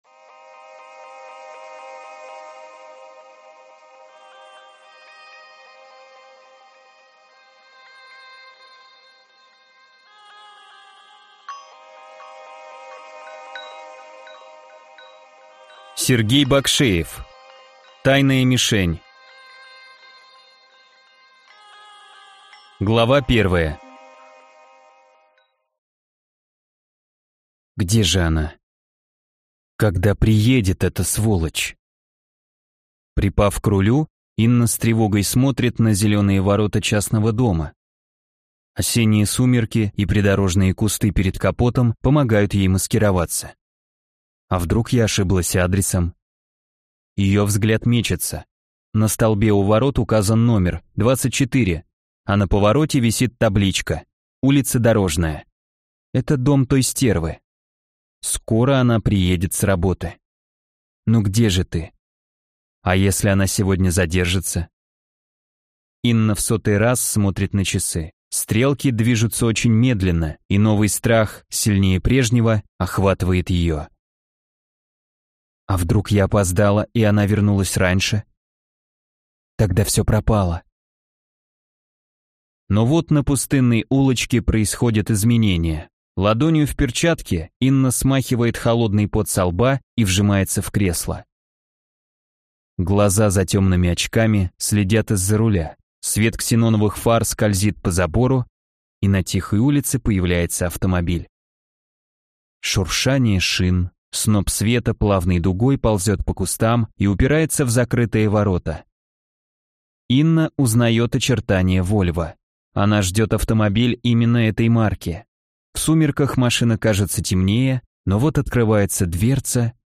Аудиокнига Тайная мишень | Библиотека аудиокниг